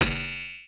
Key-fx_99.1.1.wav